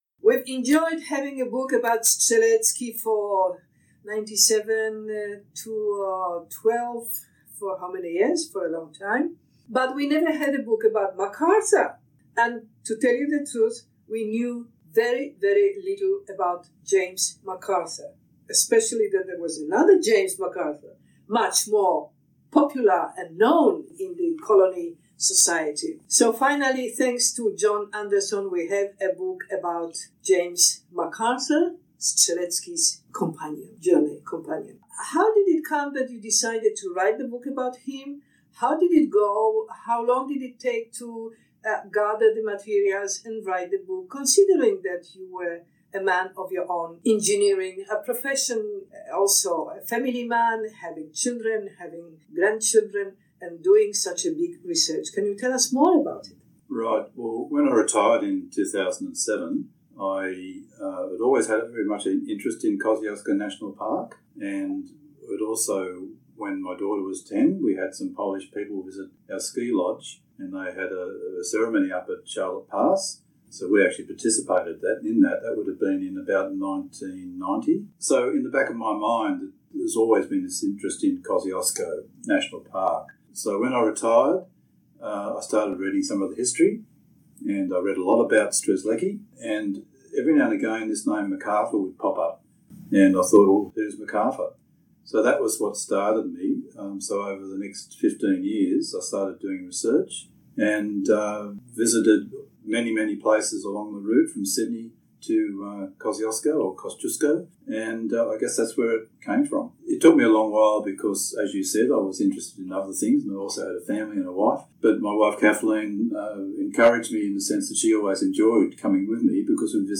AUDIO. A complete interview